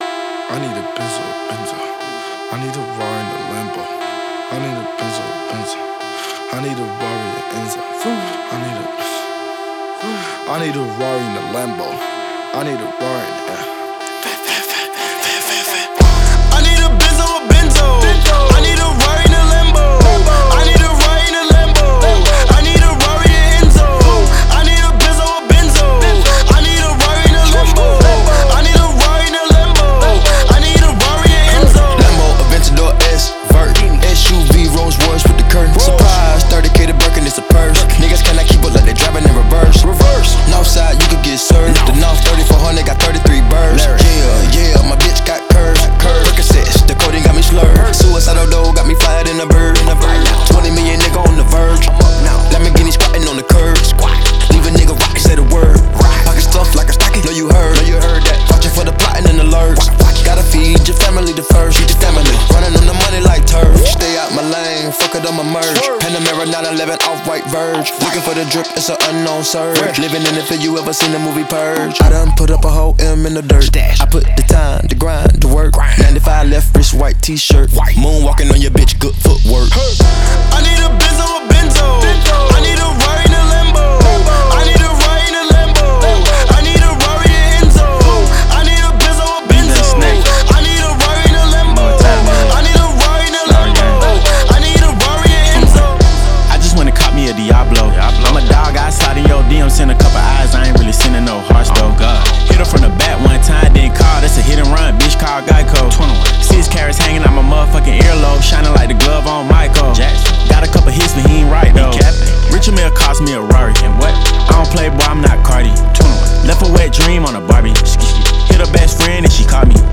previously released with vocals
puts the hip-hop out front